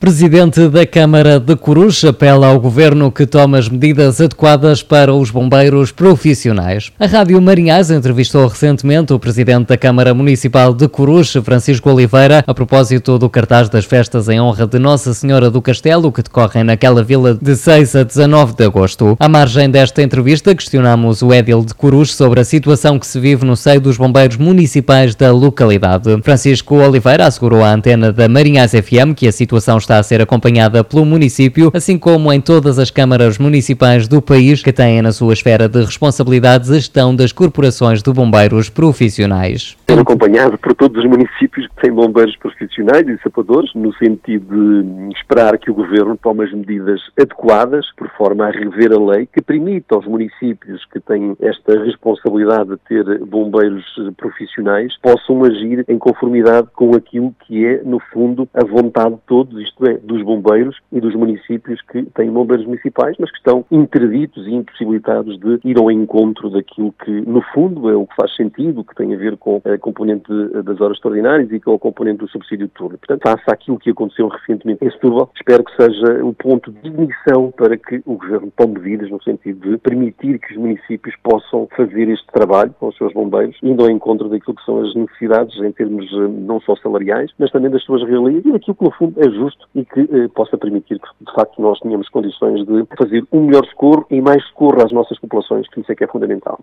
A Rádio Marinhais entrevistou, recentemente, o presidente da Câmara Municipal de Coruche, Francisco Oliveira, a propósito das Festas em Honra de Nossa Senhora do Castelo que decorrem, naquela vila, de 6 a 19 de agosto.